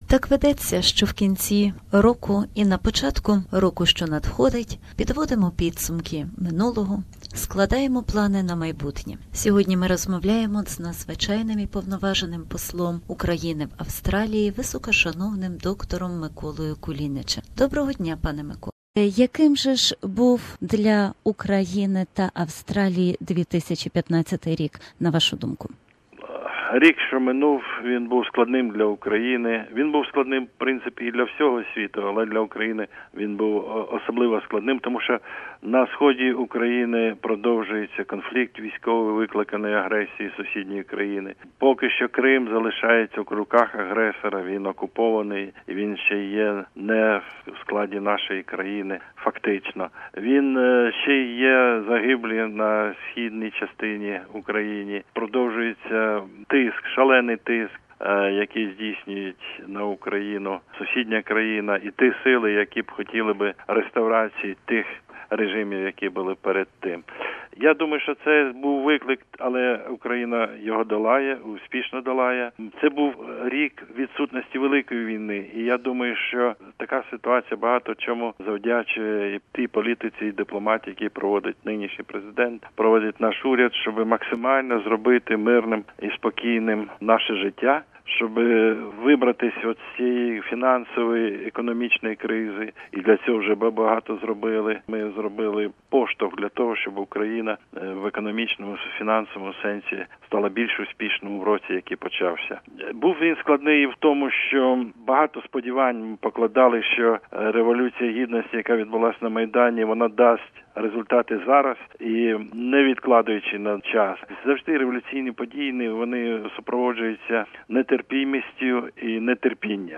Інтерв'ю з др. Миколою Кулінічем, Надзвичайним і Повноважним Послом України в Австралії.